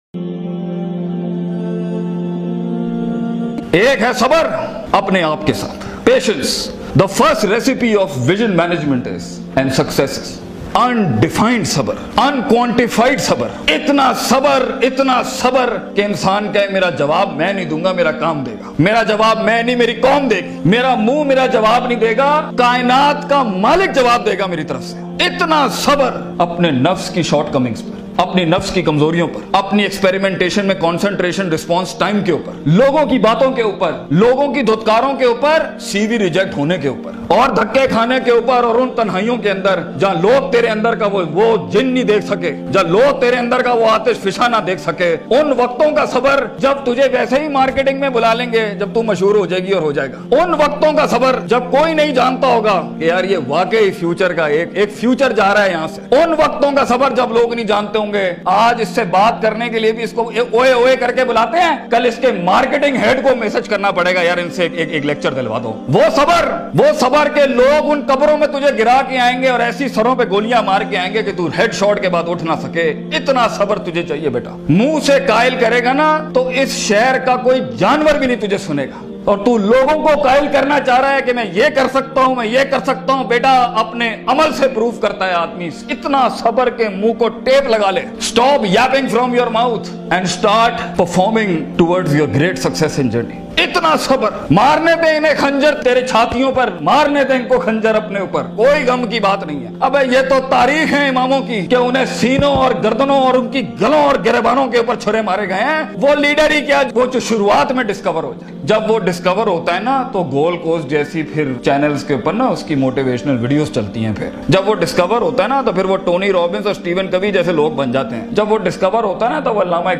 Motivational Speech
Jeet ka Safar Corporate Motivational Session Al Midrar Institute.mp3